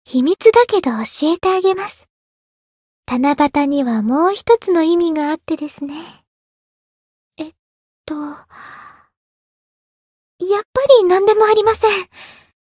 灵魂潮汐-爱莉莎-七夕（送礼语音）.ogg